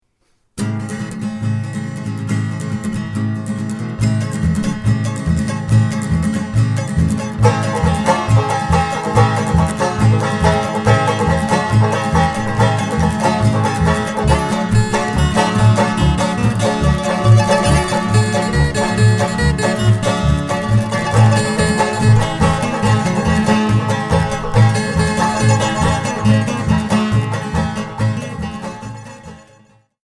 Performed on guitars, mandolins, banjos, and more